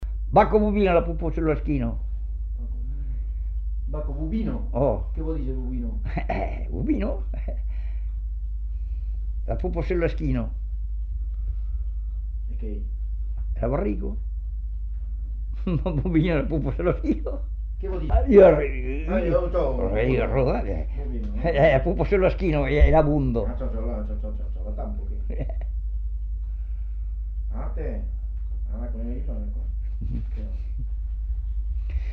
Aire culturelle : Savès
Effectif : 1
Type de voix : voix d'homme
Production du son : récité
Classification : devinette-énigme